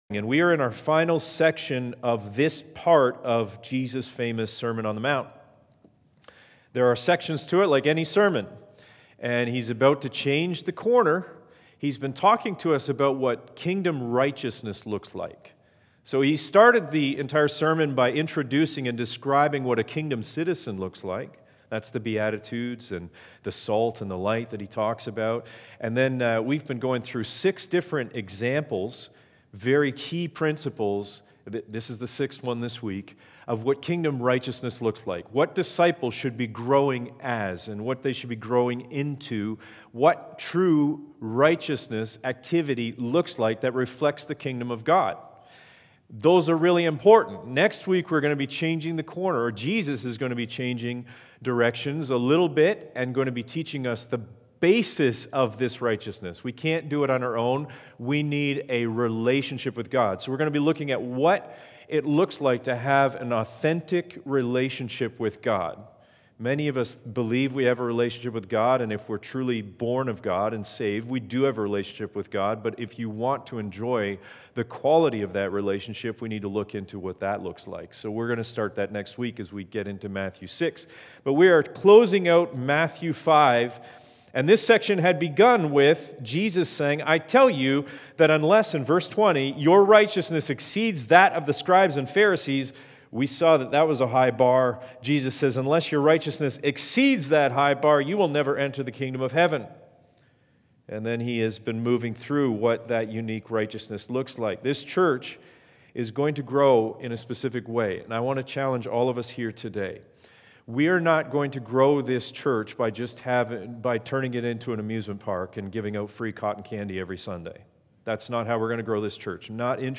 Teachings - Harvest Bible Church Paris